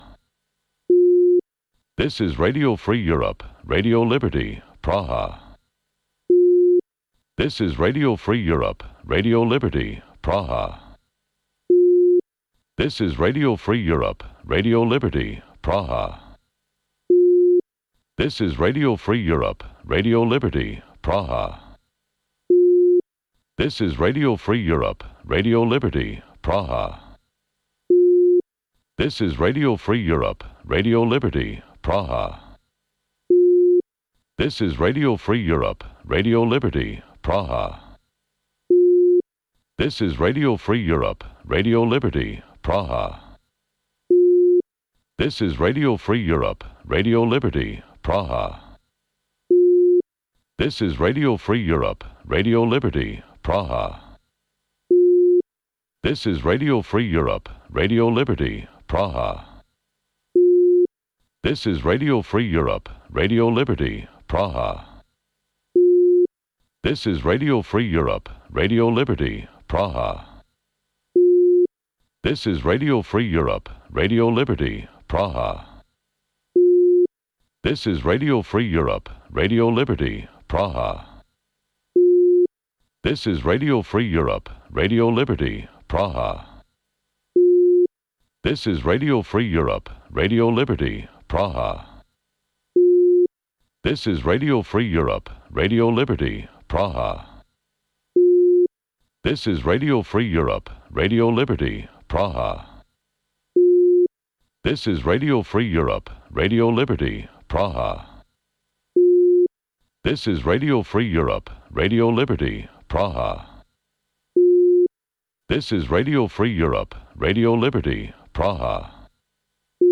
Ştiri, interviuri, analize. Programul care stabileşte agenda zilei.